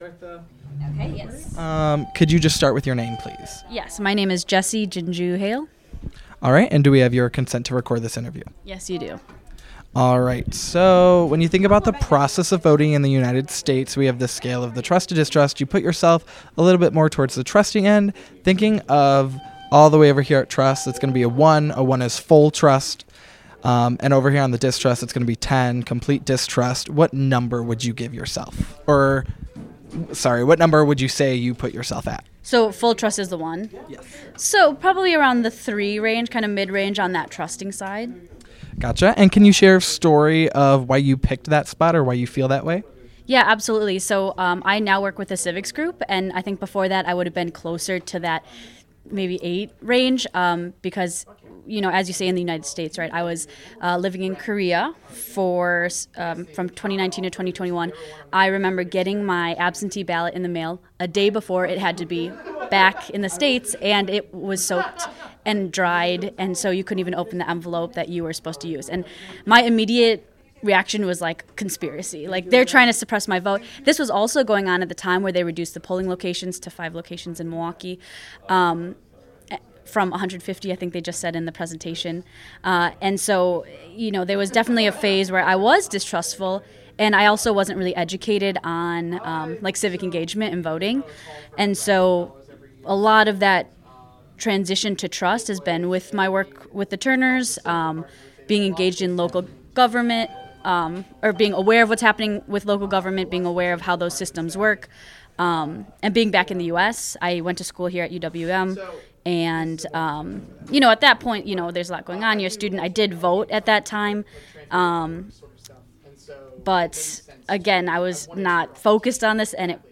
University of Wisconsin-Milwaukee